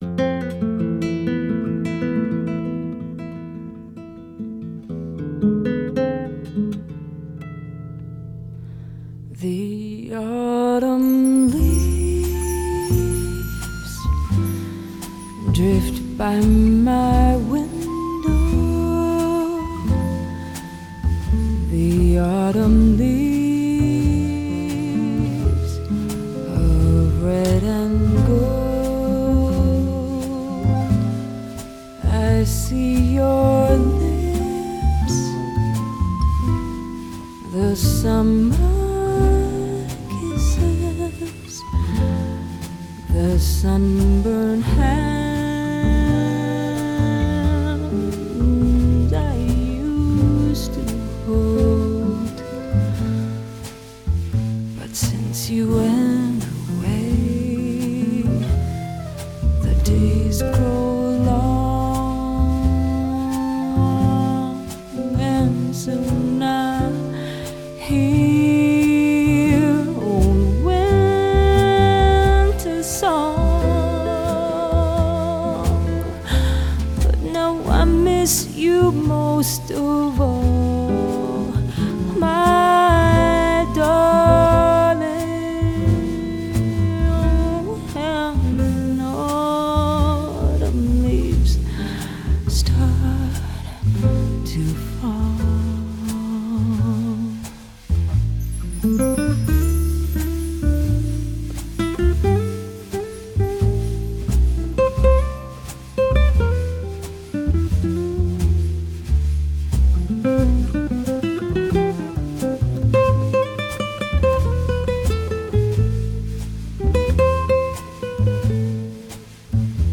Random falling leaves with background music
jazz standard